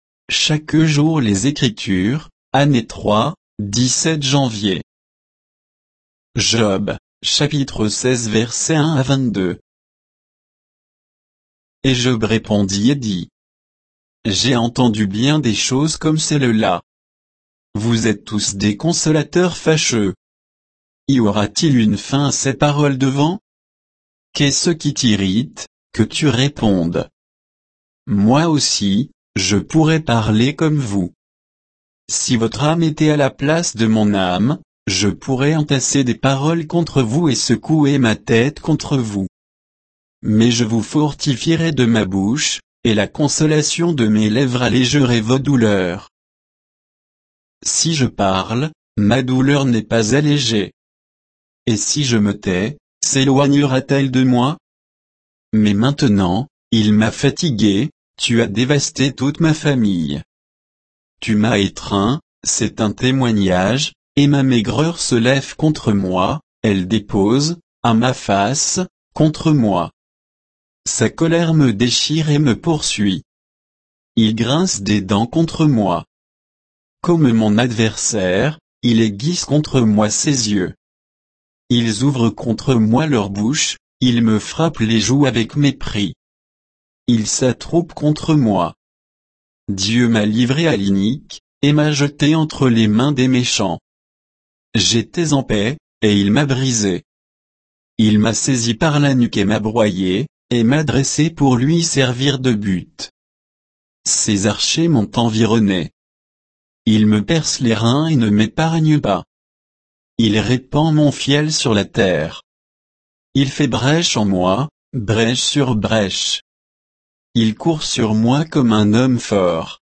Méditation quoditienne de Chaque jour les Écritures sur Job 16